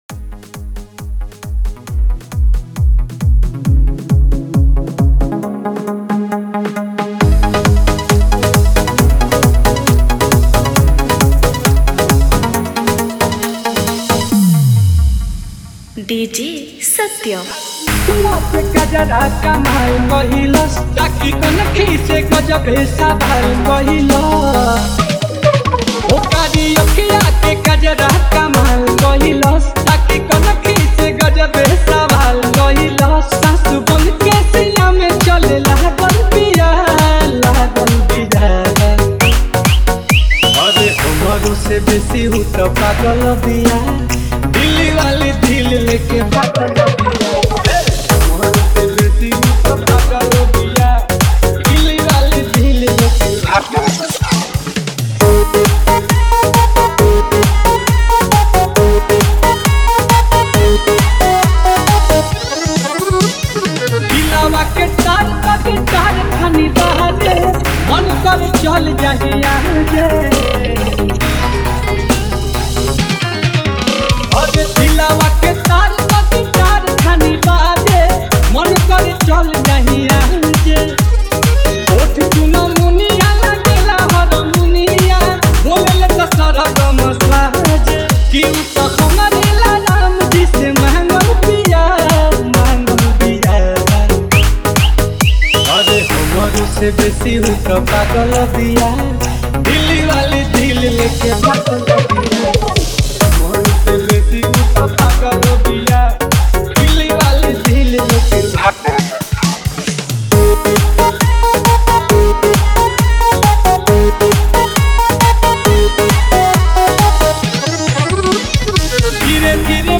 Category : Bhojpuri DJ Remix Songs